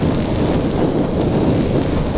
marble_roll_rubber.wav